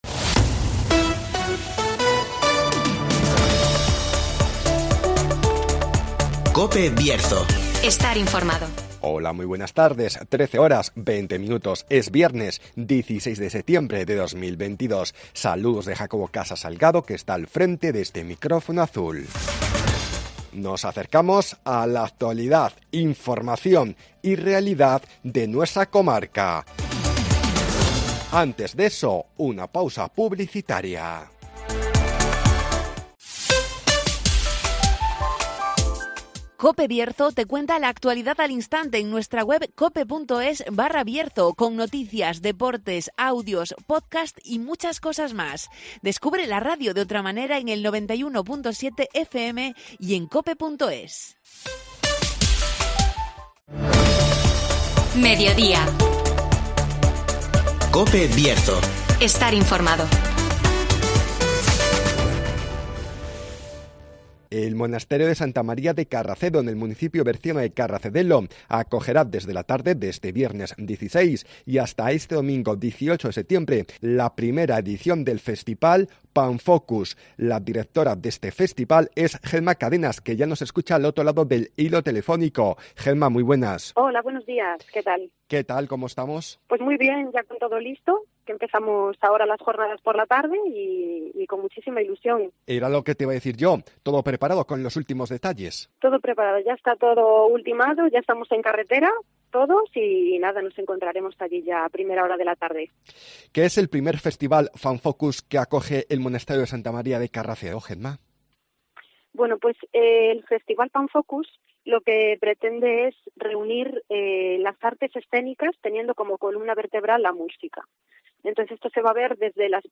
El monasterio de Carracedo acoge este fin de semana el I festival Panfocus (Entrevista